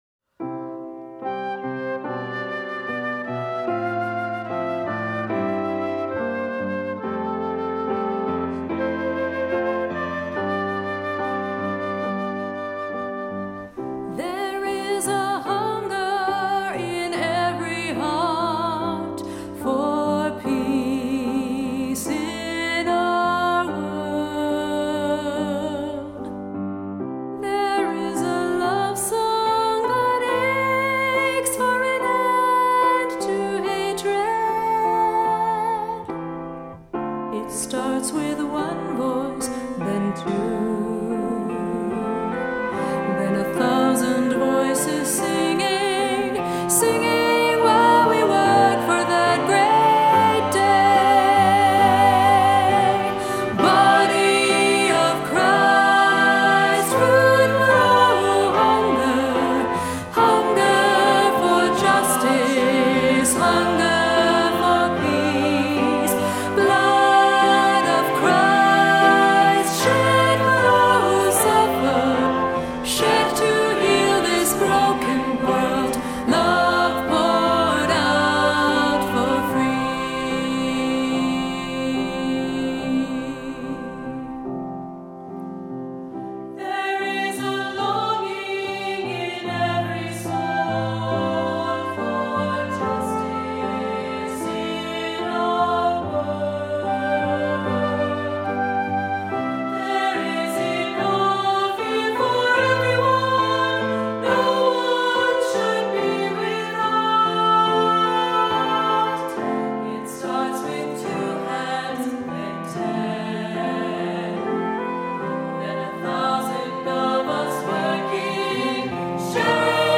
Voicing: "SATB","Soloist","Assembly"